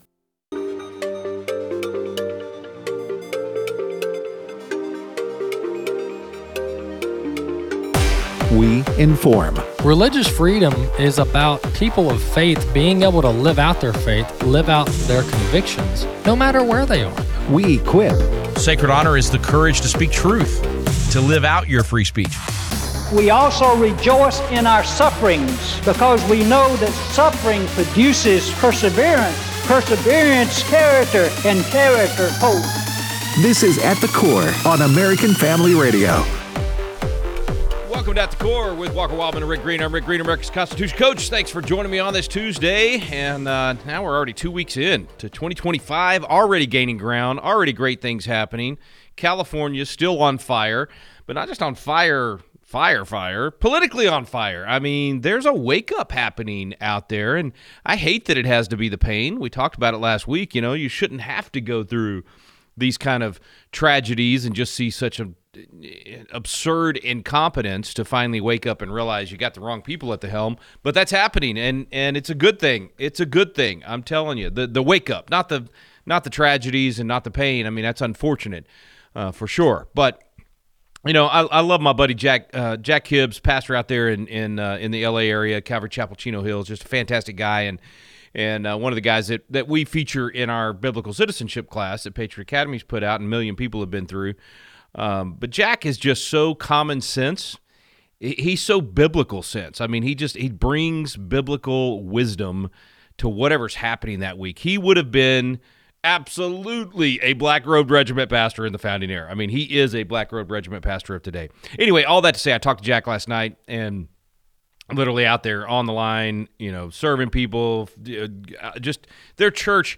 38:00 - 54:10: Discussion continues with various callers